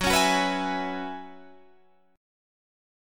Listen to F#9sus4 strummed